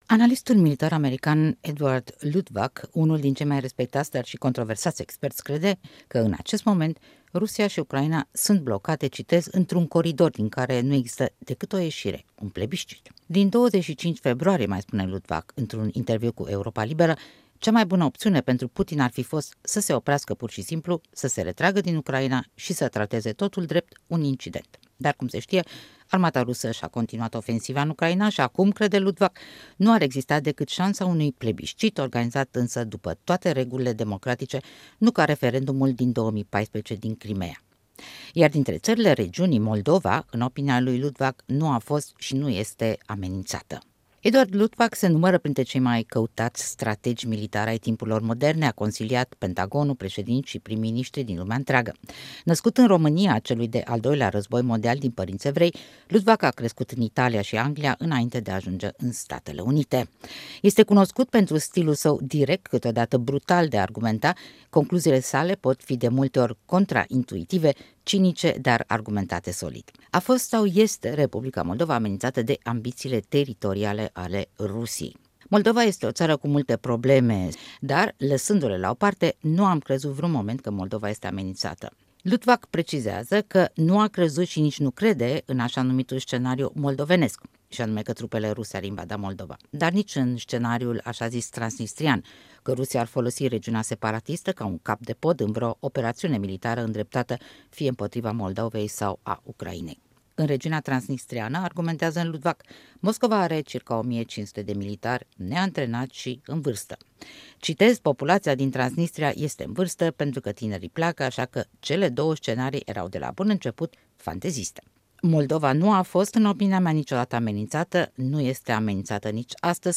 „Moldova nu a fost vreodată în pericol” – interviu cu analistul militar american Edward Luttwak